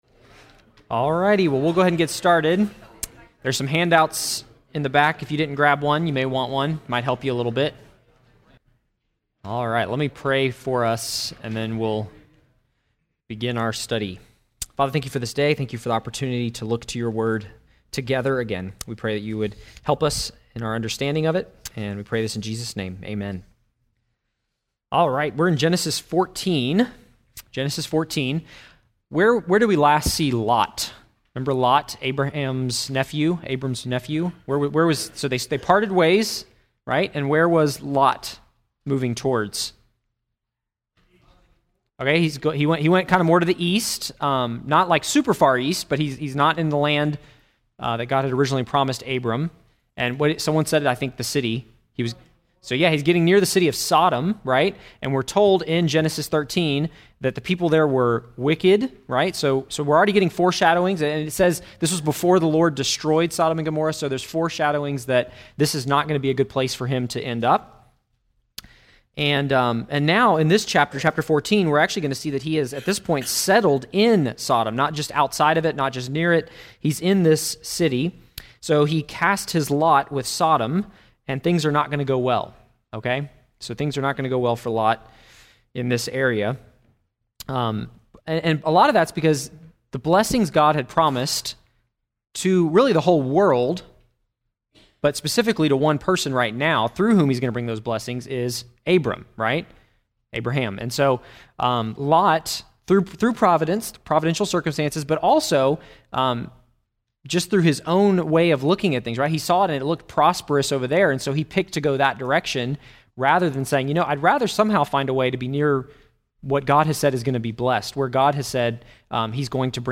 Lesson 25 in the Genesis: Foundations Sunday School class.